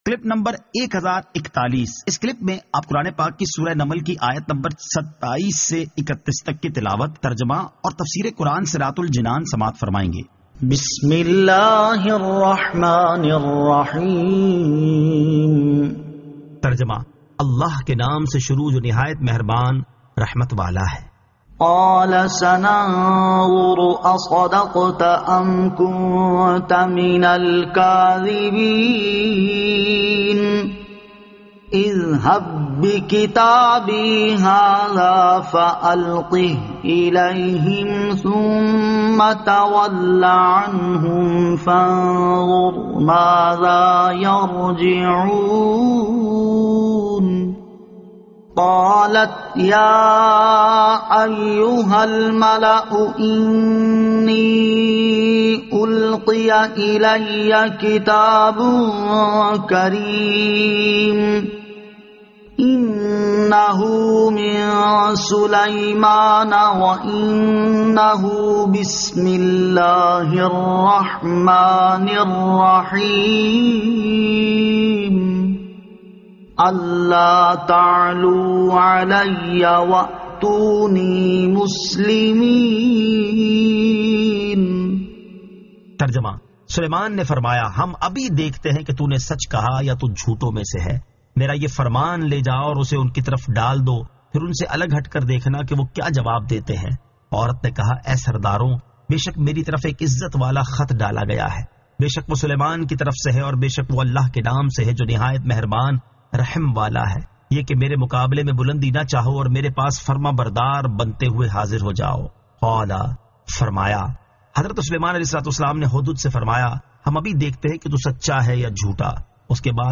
Surah An-Naml 27 To 31 Tilawat , Tarjama , Tafseer